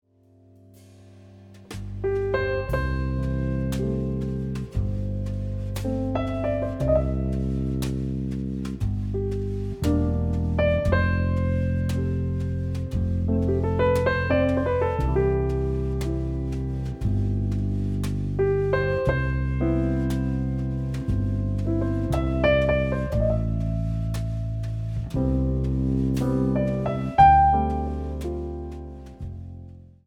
4ビートを基調としながらも、ピアノトリオによる見事な賛美歌を聴かせてくれる。